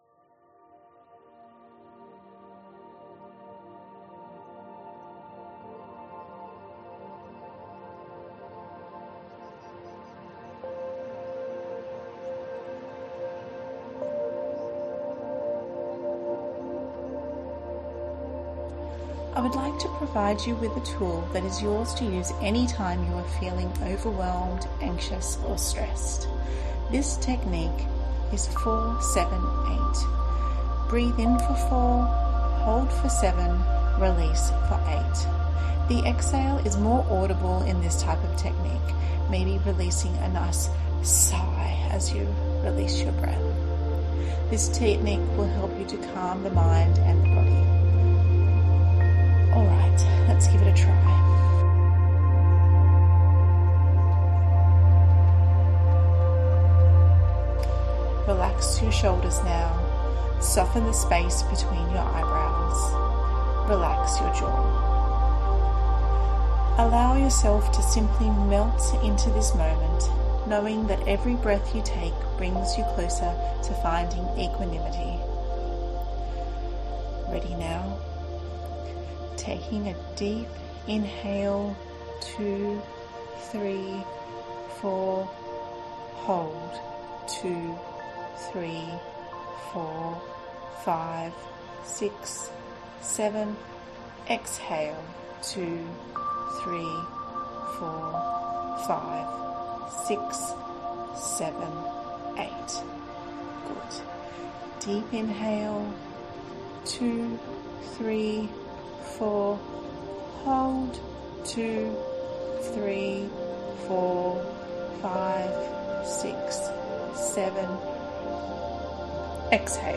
4-7-8-breathwork-for-anxiety-w-m.mp3